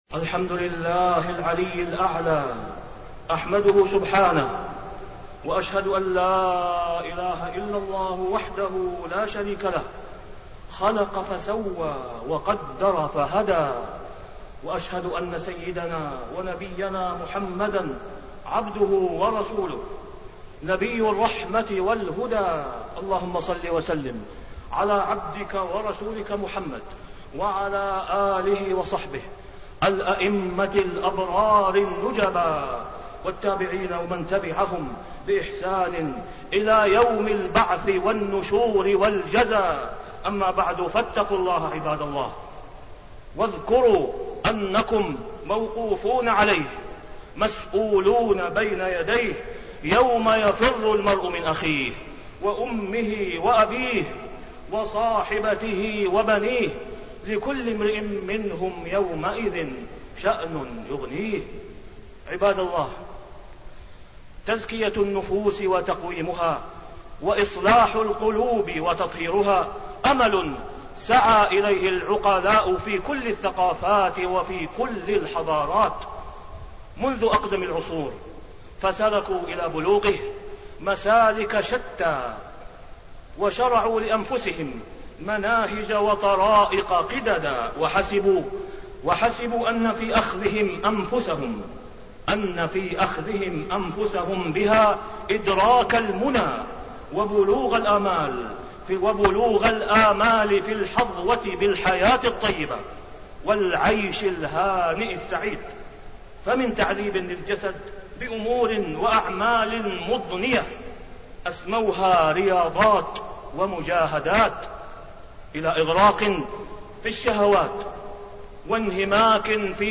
تاريخ النشر ٧ شعبان ١٤٣٢ هـ المكان: المسجد الحرام الشيخ: فضيلة الشيخ د. أسامة بن عبدالله خياط فضيلة الشيخ د. أسامة بن عبدالله خياط تزكية النفوس وإصلاح القلوب